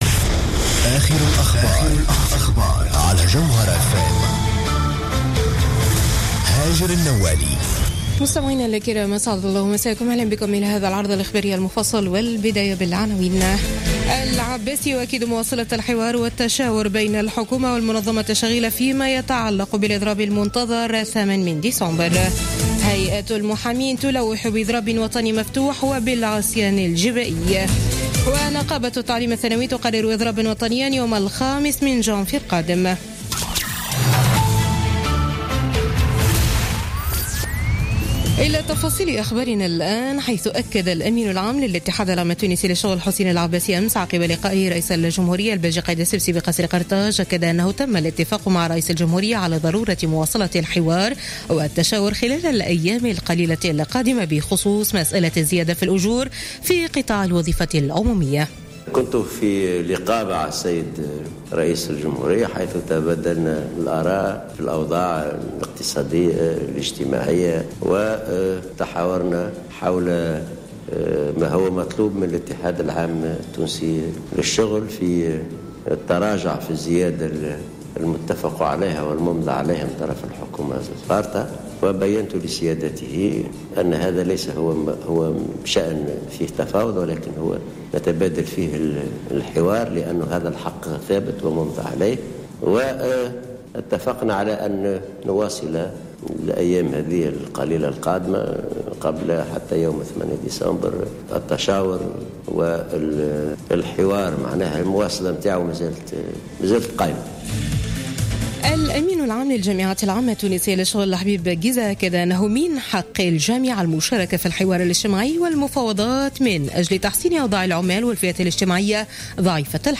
نشرة أخبار منتصف الليل ليوم الأحد 4 ديسمبر 2016